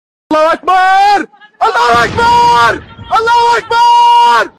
Allahu Akbar Sound Effect.mp3
allahu-akbar-sound-effect.mp3